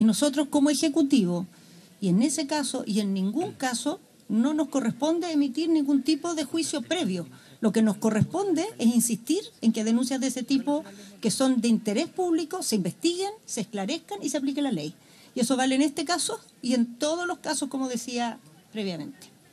Por lo mismo, en un punto de prensa, la Ministra Tohá recibió una pregunta respecto de esta situación, sobre si había conflictos de intereses.